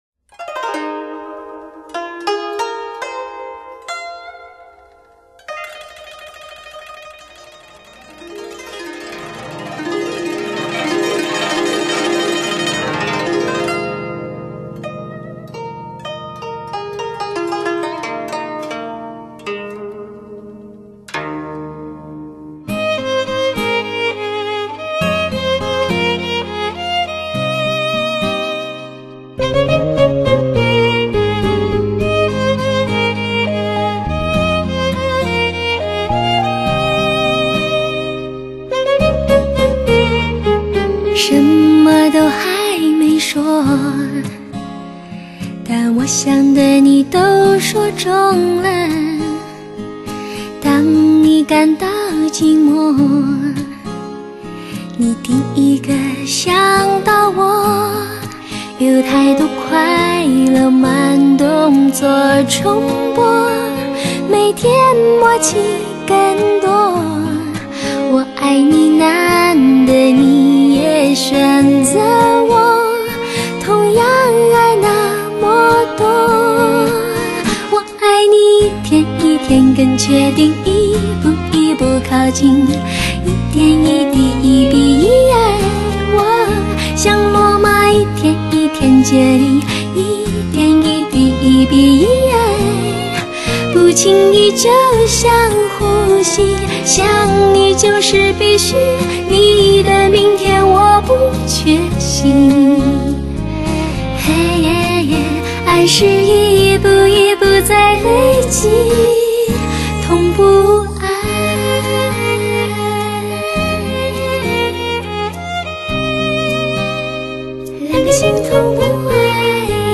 最贴心的HI-FI女声
清新典雅 飘逸秀美
令人神轻气爽的天使之声
如歌谣般旋律优美、流畅清新的曲子，